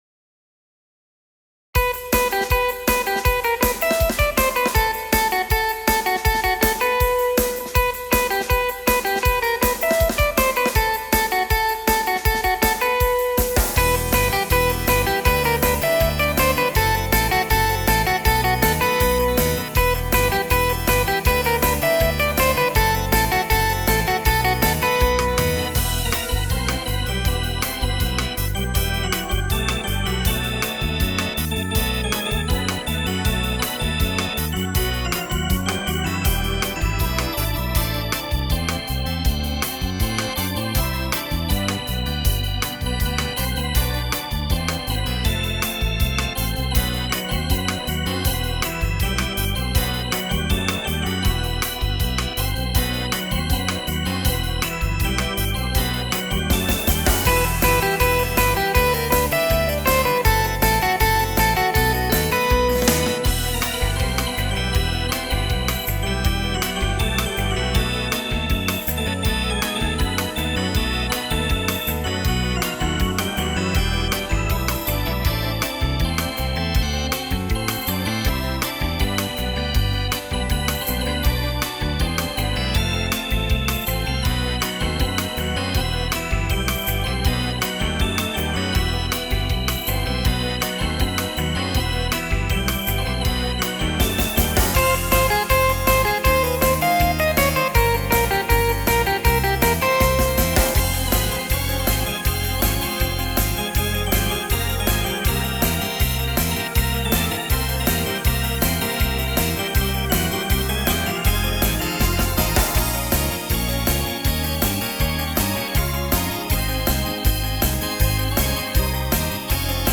инструментальная версия (синтезатор)